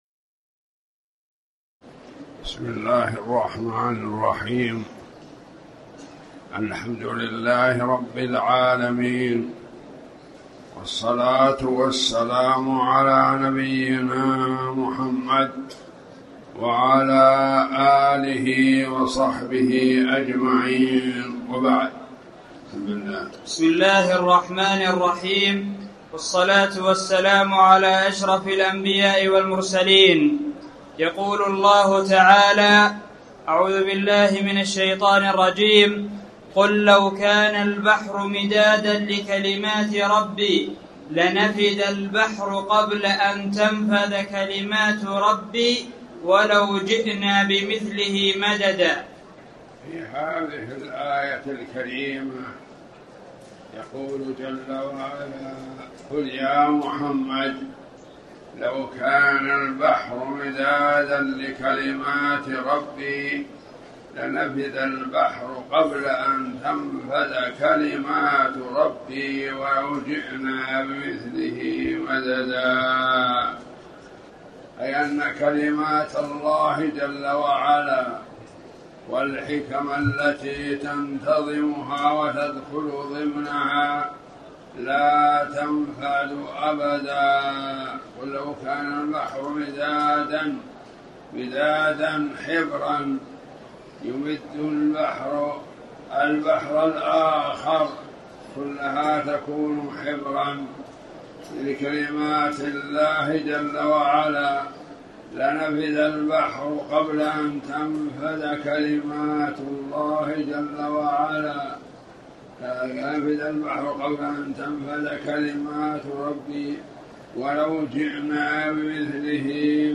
تاريخ النشر ٣٠ ربيع الثاني ١٤٣٩ هـ المكان: المسجد الحرام الشيخ